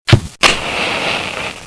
'Title=allumette'
B_FEU.mp3